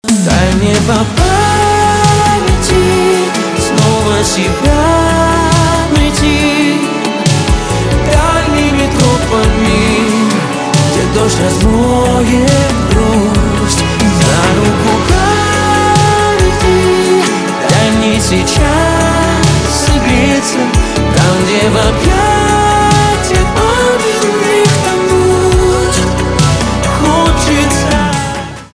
поп
грустные , чувственные , битовые , гитара